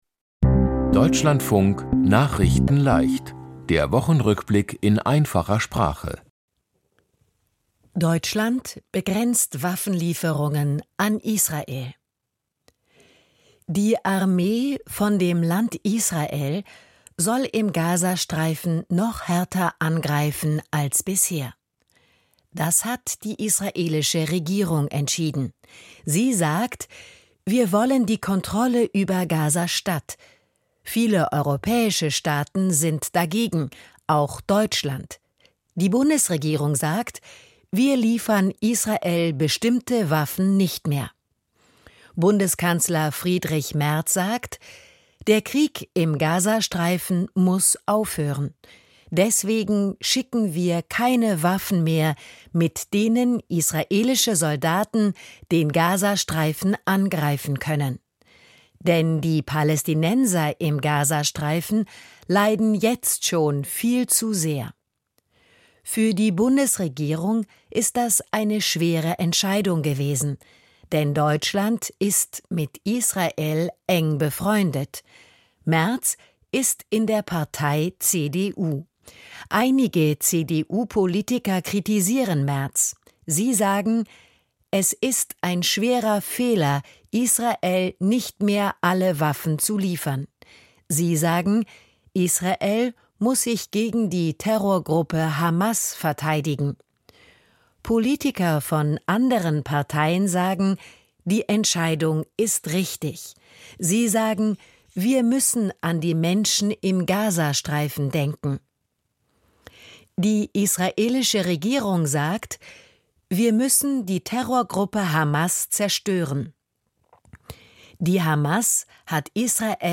Die Themen diese Woche: Deutschland begrenzt Waffen-Lieferungen an Israel, Trump und Putin wollen über den Krieg in der Ukraine sprechen, Viele Wald-Brände im Süden von Europa, 23 Länder in Afrika kämpfen gegen die Krankheit Cholera, Viele Probleme mit dem E-Rezept und Fußballerinnen und Fußballer des Jahres gewählt. nachrichtenleicht - der Wochenrückblick in einfacher Sprache.